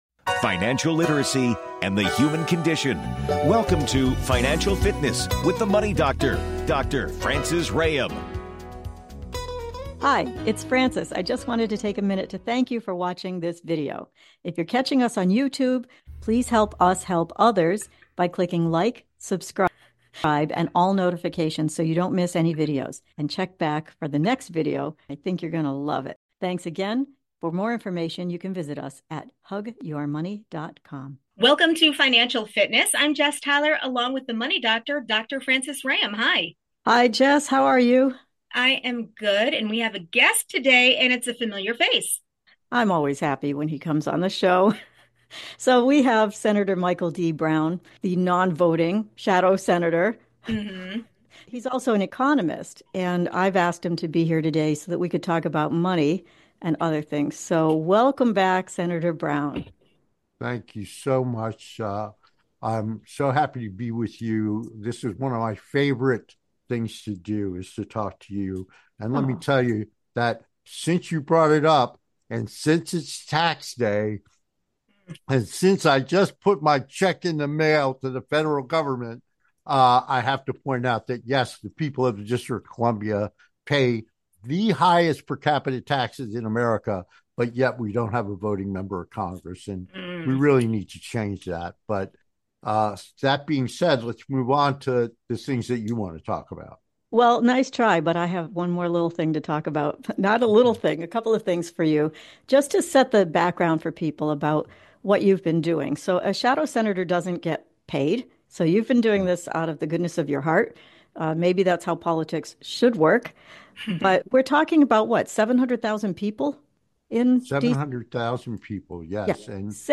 Guest, Senator Michael D. Brown on Economy